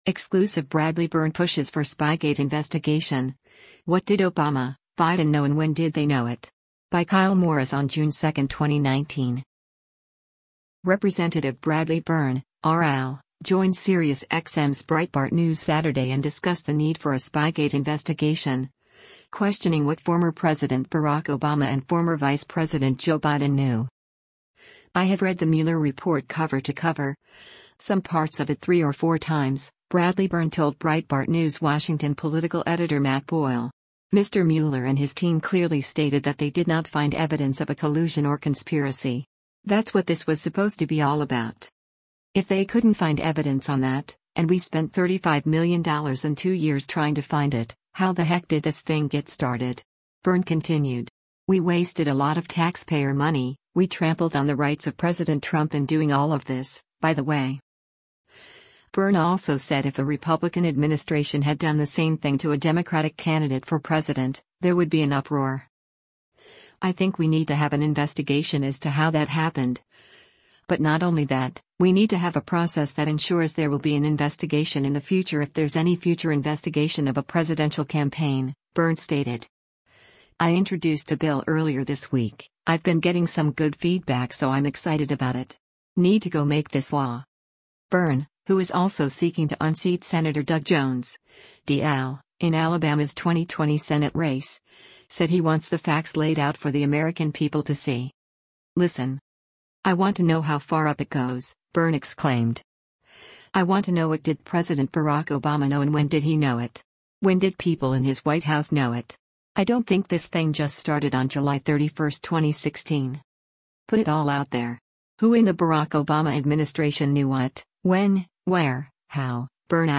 Rep. Bradley Byrne (R-AL) joined SiriusXM’s Breitbart News Saturday and discussed the need for a Spygate investigation, questioning what former President Barack Obama and former Vice President Joe Biden knew.
Throughout the interview, Byrne also weighed in on recent reports of Roy Moore’s consideration of a 2020 United States Senate run.